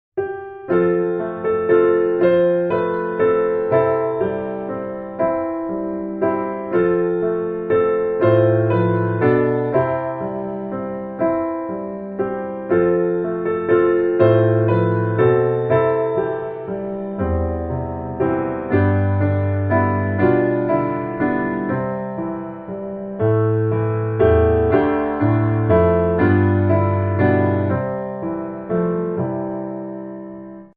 Piano Hymns
C Majeur